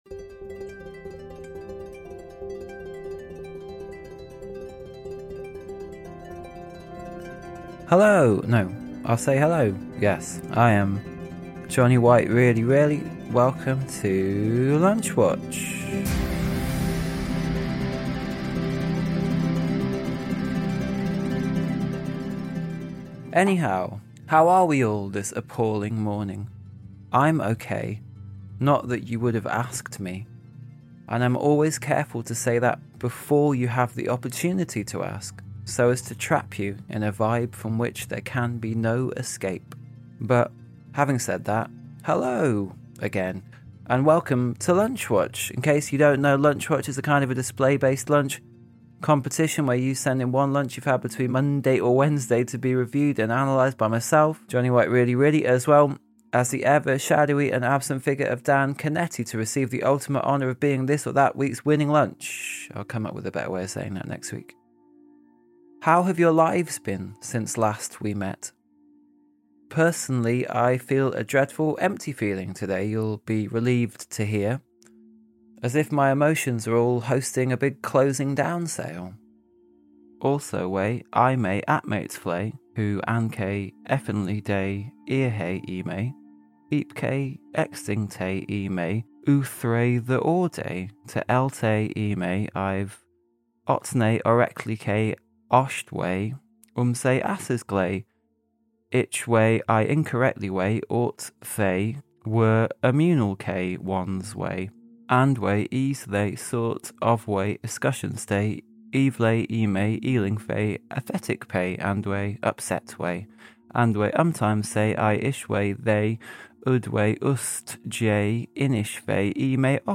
With original music
The coin flip was performed